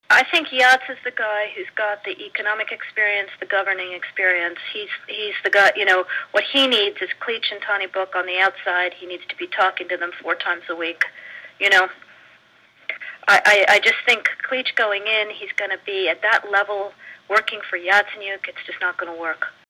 Voce feminină despre atribuită Victoriei Nuland